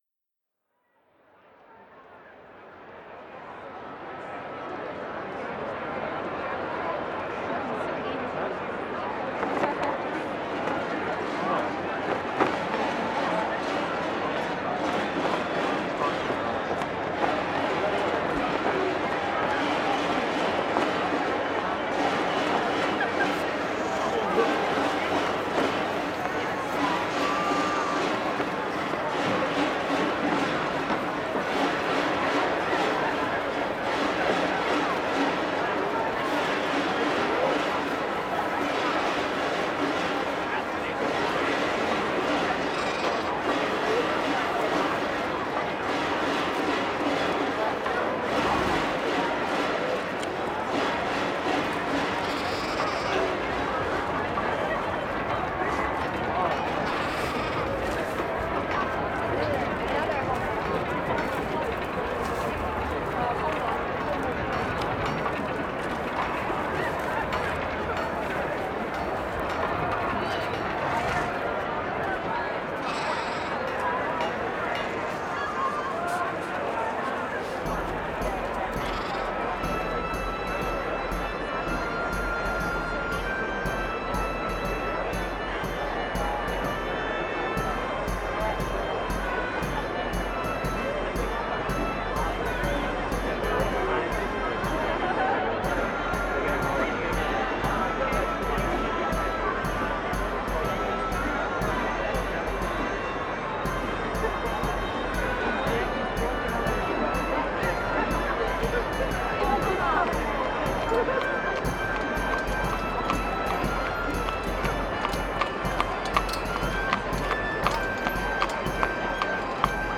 Здесь вы услышите гул мостовых, крики торговцев, звон трамваев и другие характерные шумы ушедших эпох.
Шум средневековой ярмарки